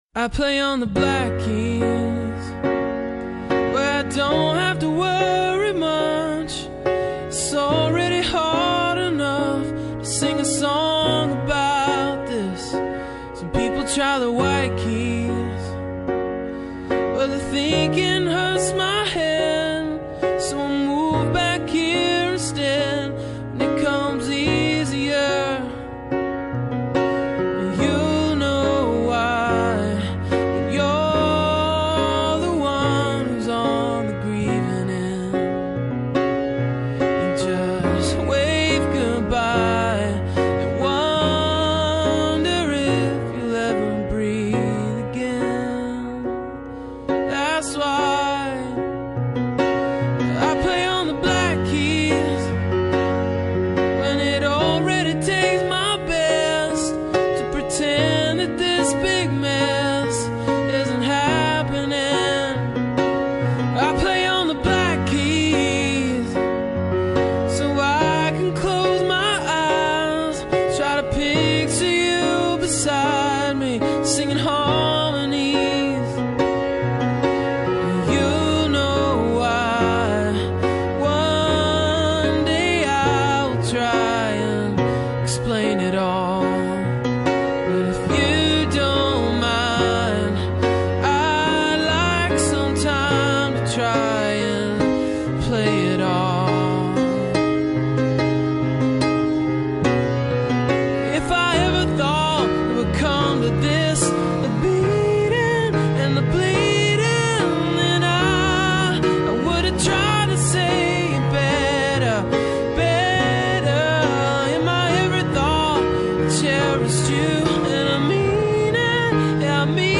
Posted in Indie Rock, piano on April 11th, 2007 3 Comments »